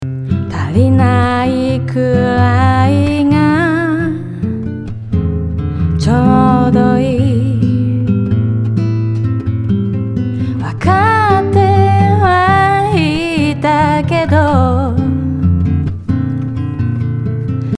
同じリバーブをボーカルにだけモノラルで別にかけた今回